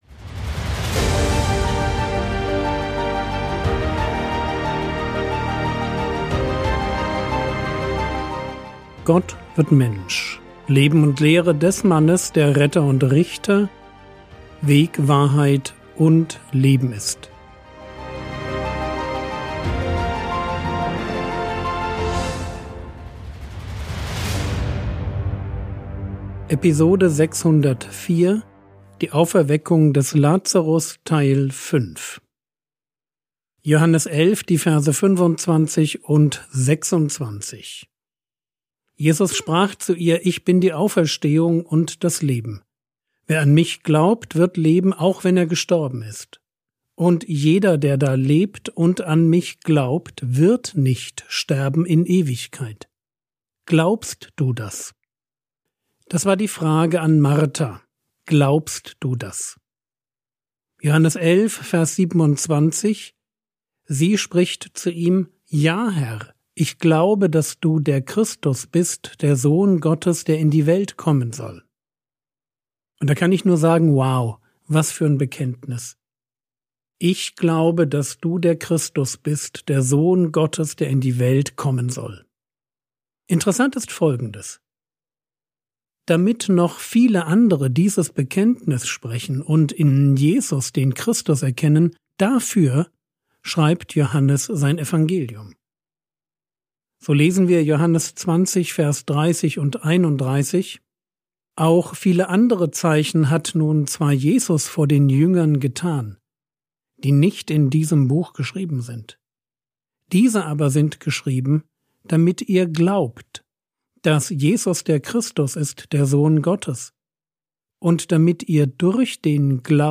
Episode 604 | Jesu Leben und Lehre ~ Frogwords Mini-Predigt Podcast